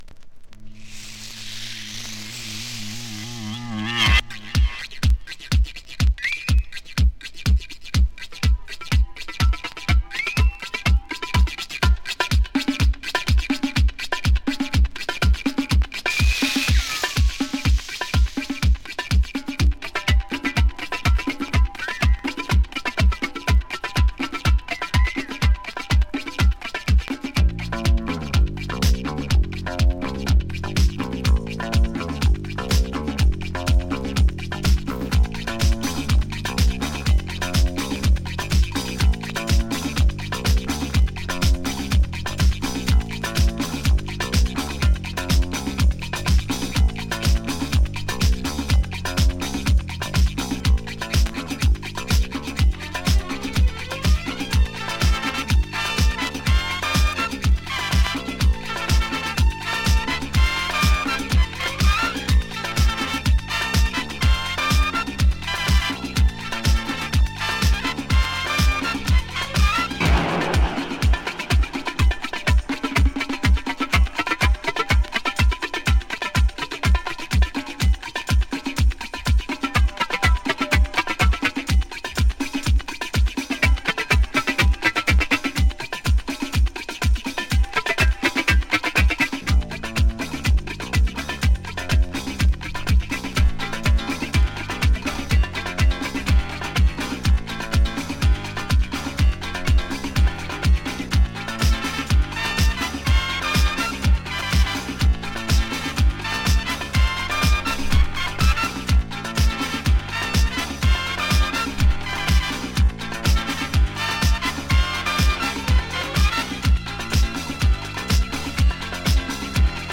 Italo Disco Classic!
【ITALO DISCO】
(Instrumental)